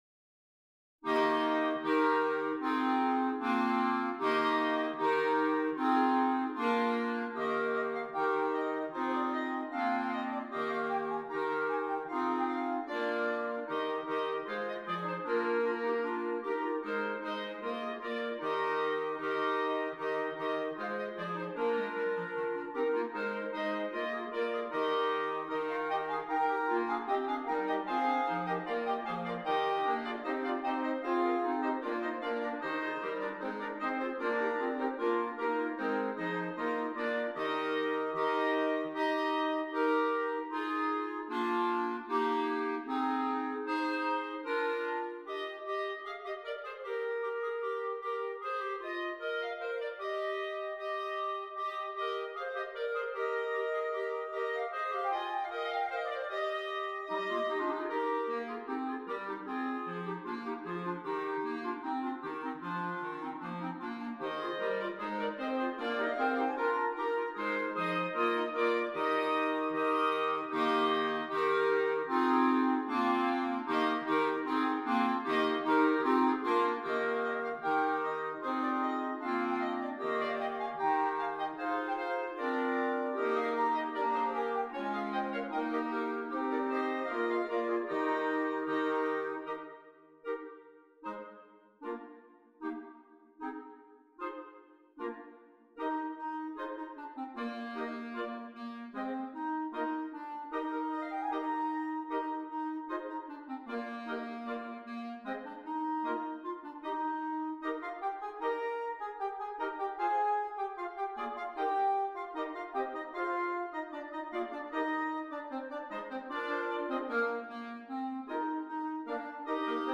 Christmas
6 Clarinets
Traditional Carol